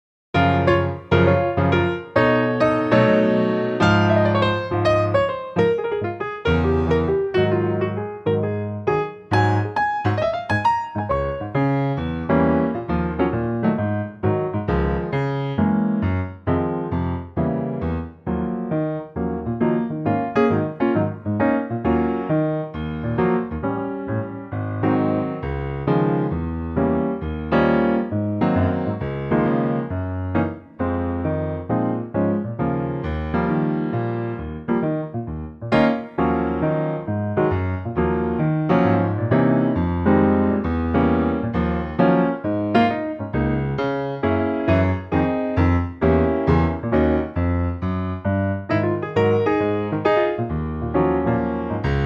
Unique Backing Tracks
key - Db - vocal range - Bb to Db
The old standard in a piano only arrangement.